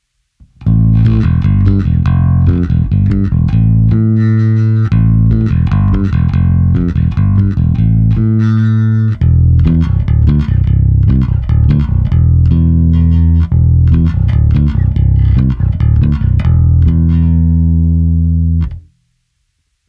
Nahrávky jsou bez simulace aparátu, nicméně jsou prohnané skrz kompresor a lampový preamp a použil jsem individuálně pro každou nahrávku i korekce přímo na baskytaře. Použité struny jsou nové niklové pětačtyřicítky Elixir.
Cívky 1, 2 a 3 – originální zvuk Lakland